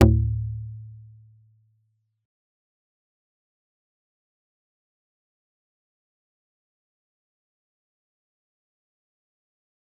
G_Kalimba-D2-mf.wav